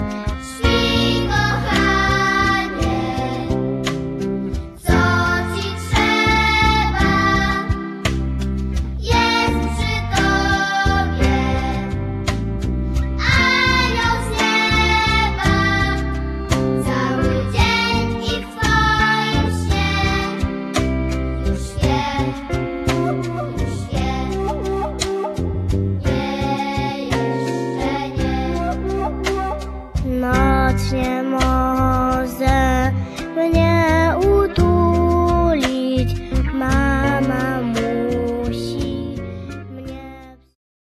dwudziestoosobowe, muzykujące, szalone przedszkole!
perkusja
akordeon
kobza ośmiostrunowa
dzieciaki - śpiew